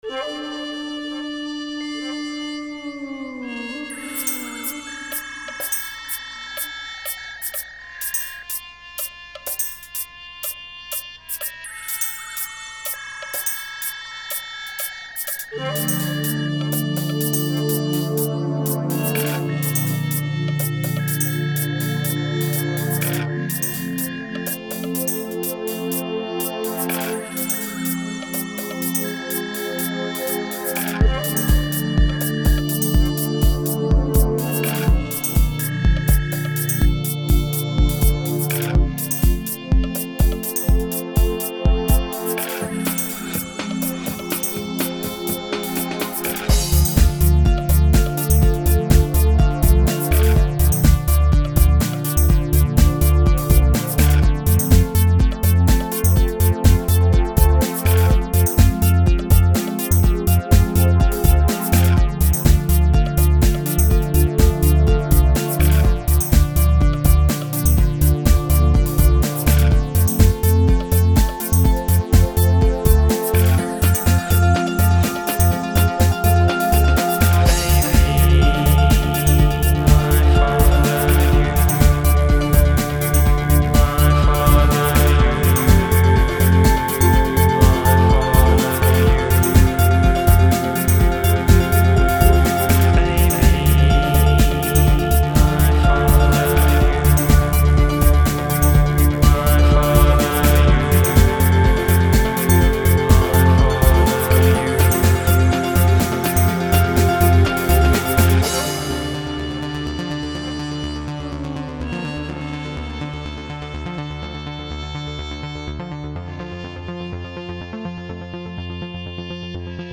music made by me on my computer over the years.